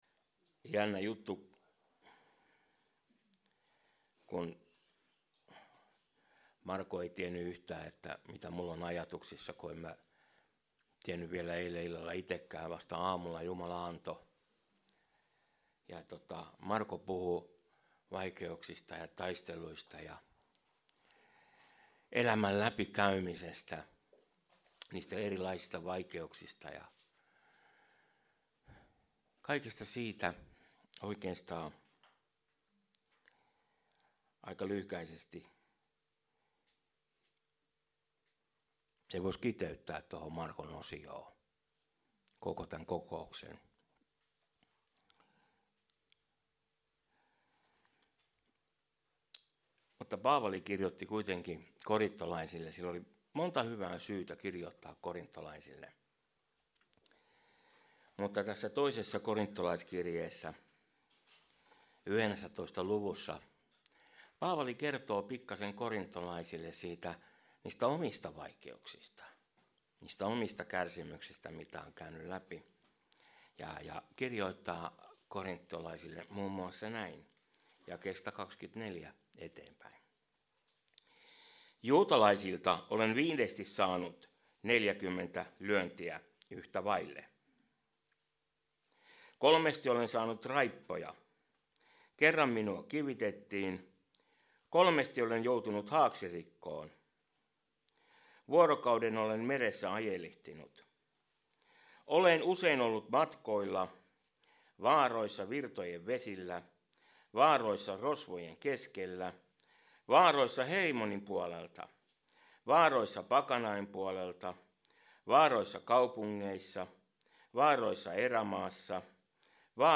Service Type: Jumalanpalvelus